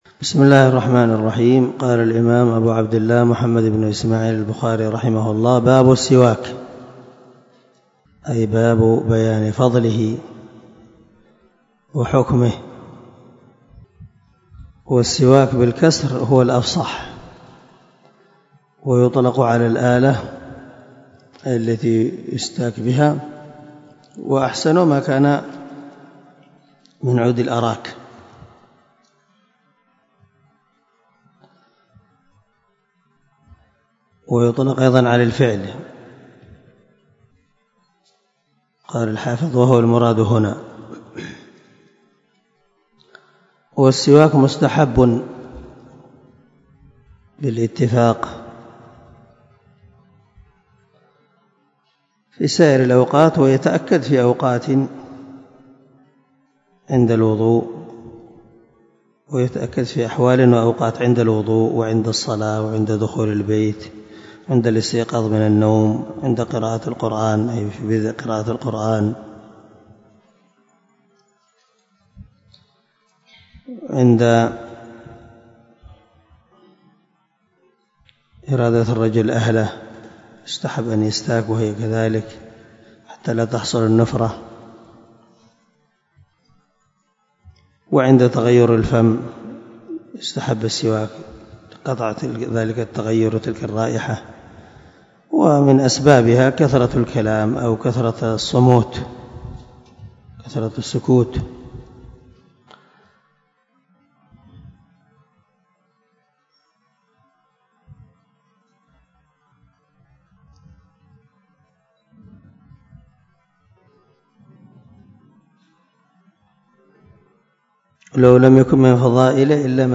204الدرس 80 من شرح كتاب الوضوء حديث رقم ( 244 ) من صحيح البخاري